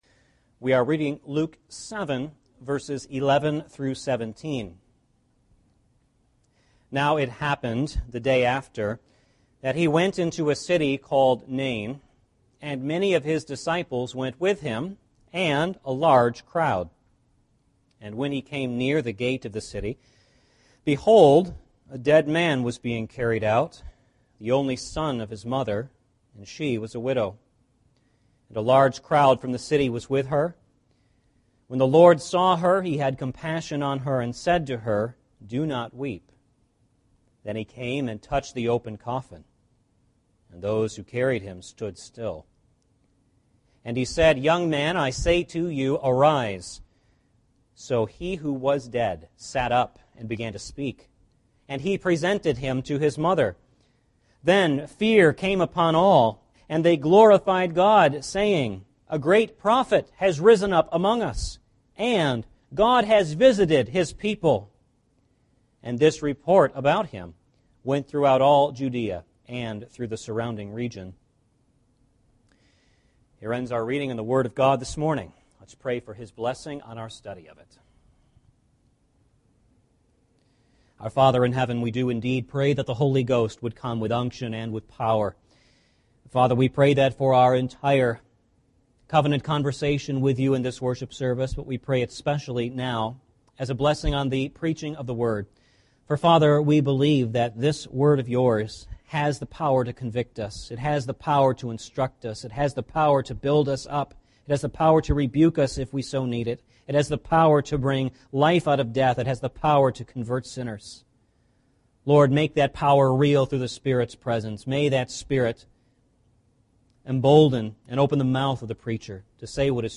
Luke 7:11-17 Service Type: Sunday Morning Service « Death’s Sting and the Grave’s Victory Where Sin Abounded…